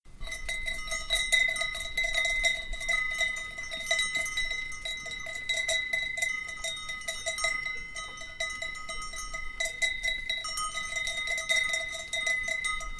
Dans cette page nous offrons des sonneries issues d’enregistrements de troupeaux.
Crète : 2 chèvres, cloche à 2 battants et kypri
crete_chevres-e17.m4a